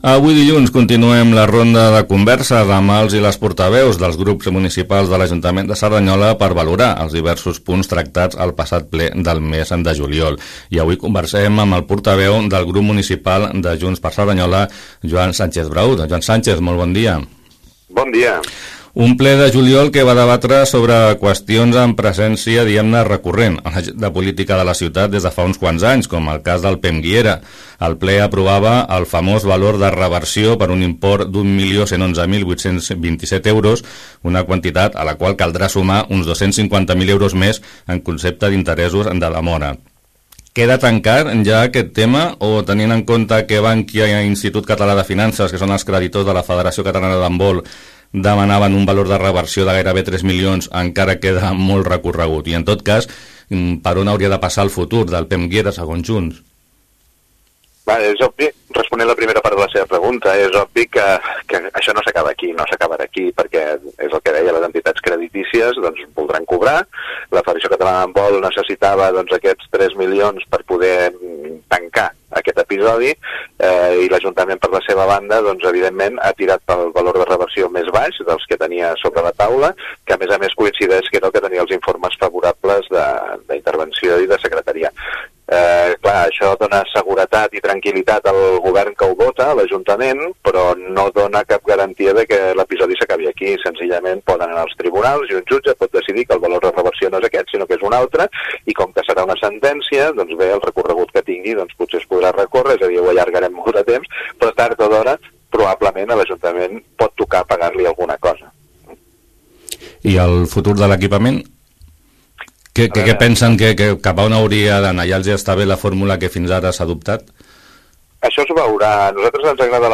Entrevista-Joan-Sánchez-Braut-JxC-Ple-juliol.mp3